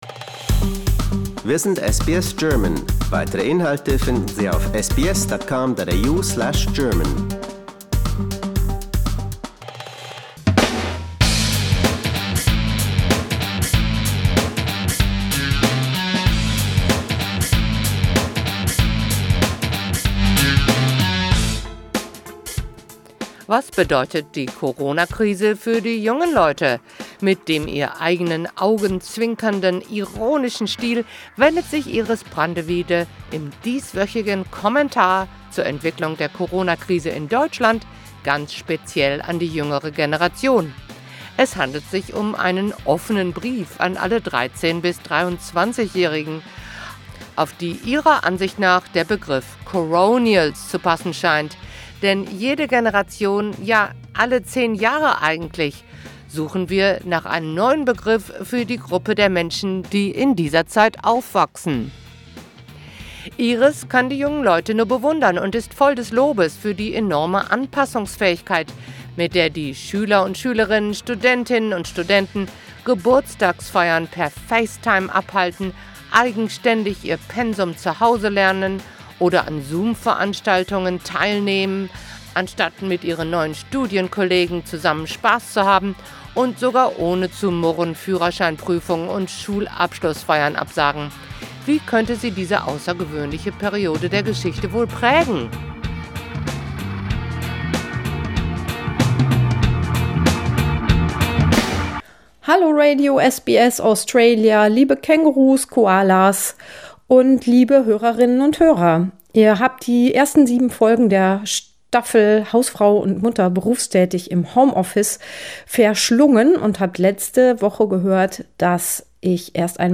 Was bedeutet die Corona-Krise für die jungen Leute? Mit dem ihr eigenen augenzwinkernden ironischen Stil
Kommentar